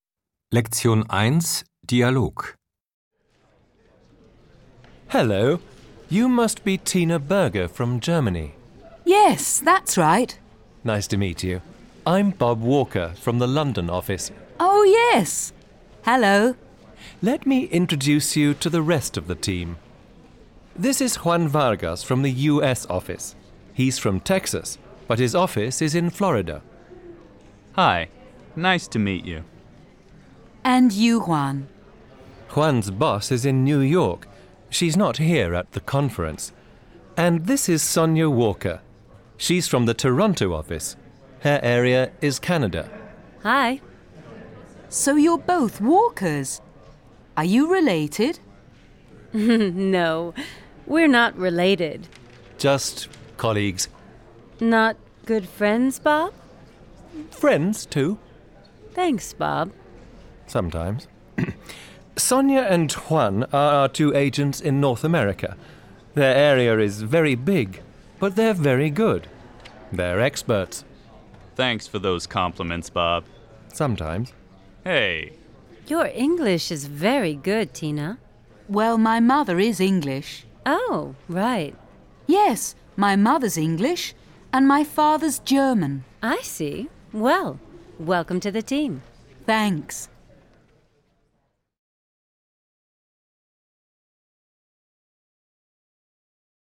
4 Audio-CDs mit Dialogen in zwei Sprechgeschwindigkeiten und abwechslungsreichem Hör- und Sprechtraining